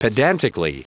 Prononciation du mot pedantically en anglais (fichier audio)
Prononciation du mot : pedantically